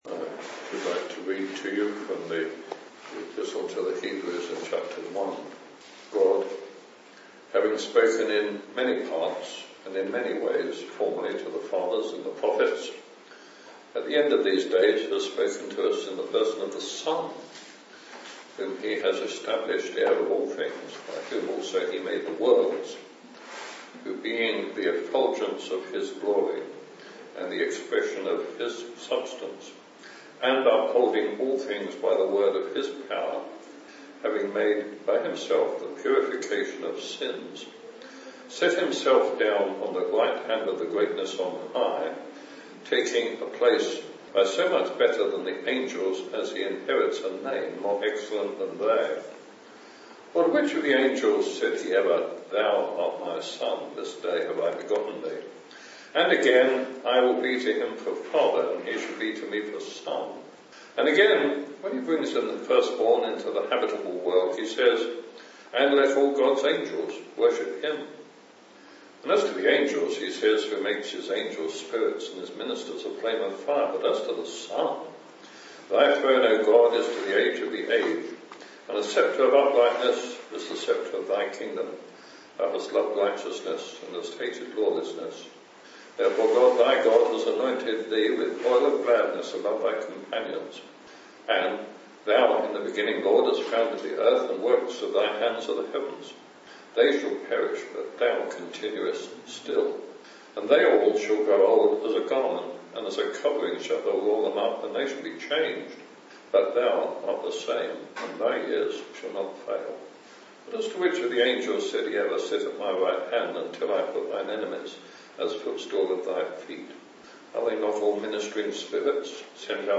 In this Gospel preaching, you will hear about Christ’s work, Christ’s coming and Christ’s Companions.